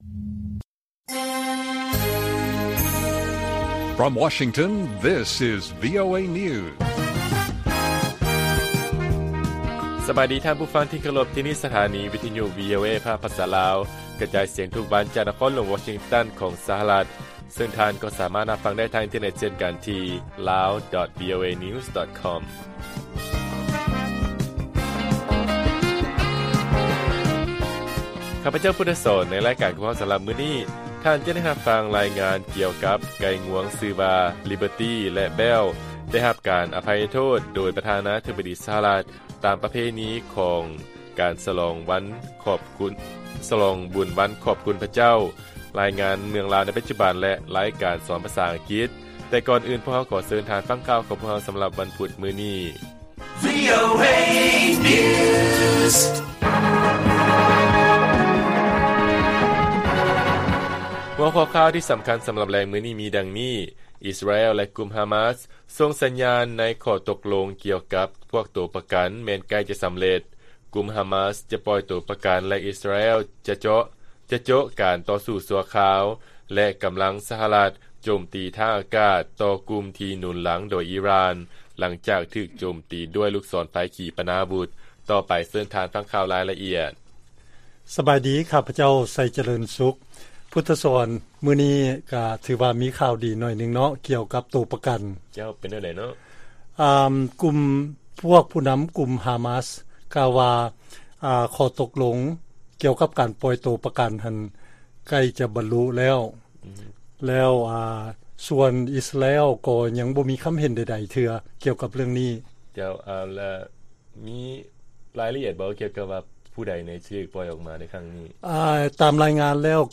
ລາຍການກະຈາຍສຽງຂອງວີໂອເອ ລາວ ວັນທີ 22 ພະຈິກ 2023